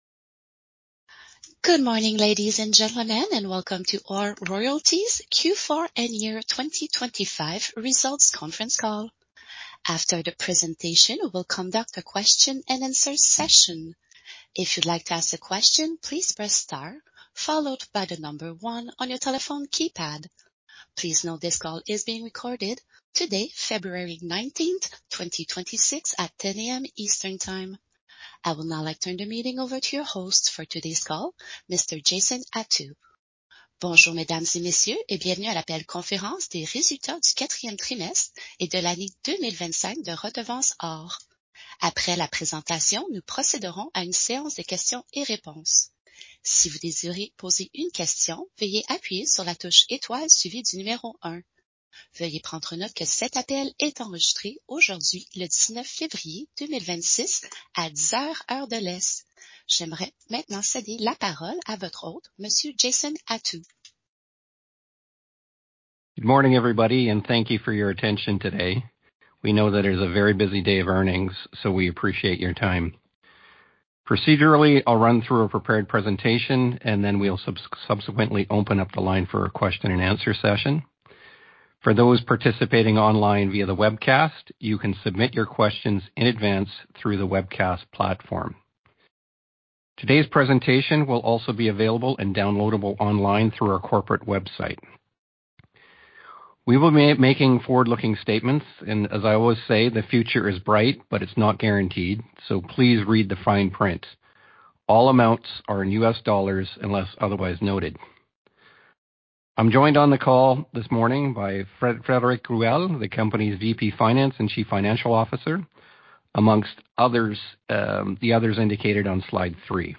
Q4 and Year 2025 Results Conference Call and Webcast